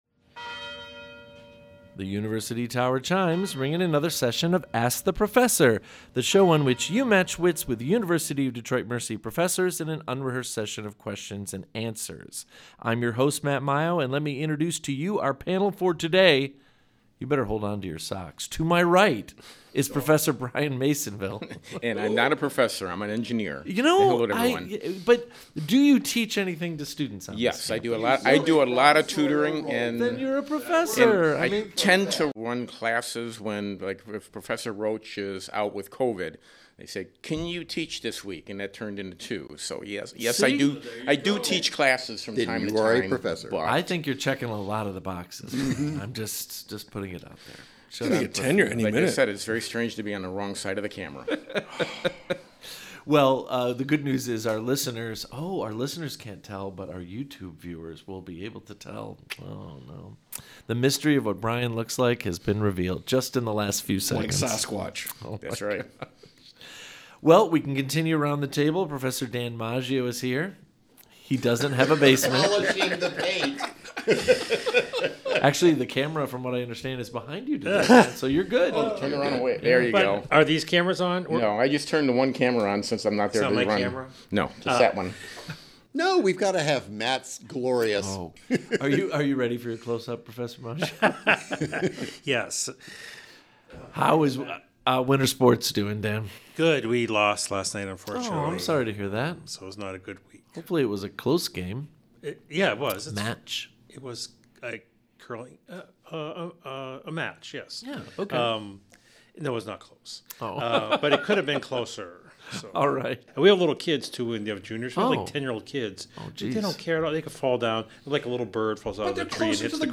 University of Detroit Mercy's broadcast quiz show